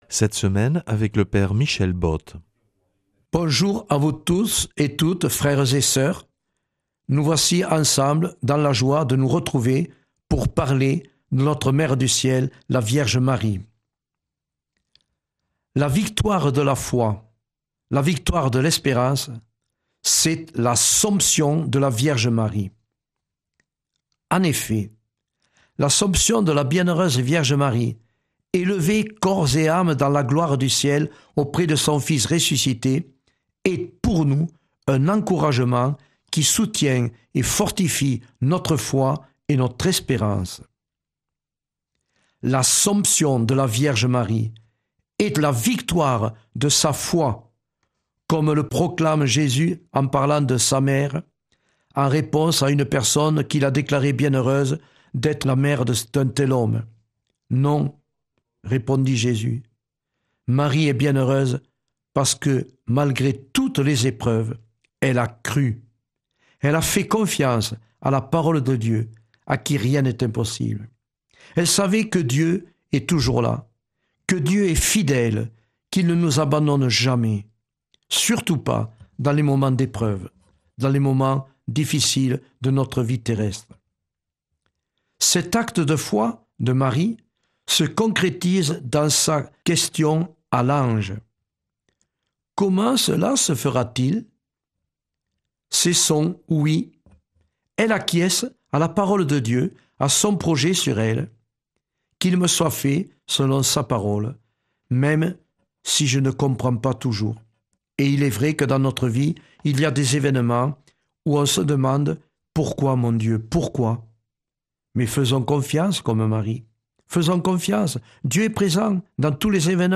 jeudi 5 février 2026 Enseignement Marial Durée 10 min